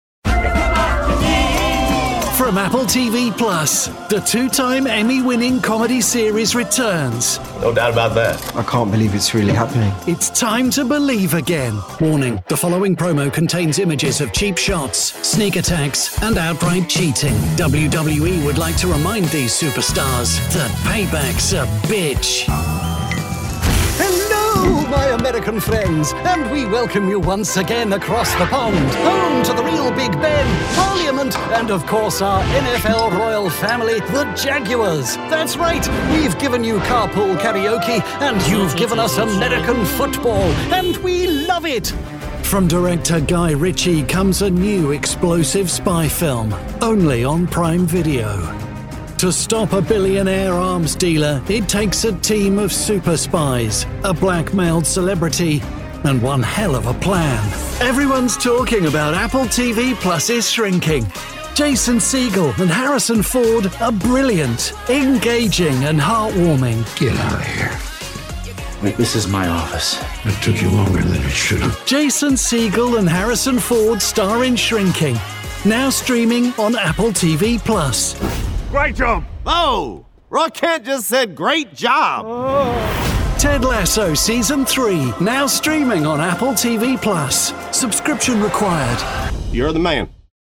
Promos
Experienced, conversational voice actor with warmth and integrity.
Professional Sound Booth